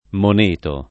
moneto [ mon % to ]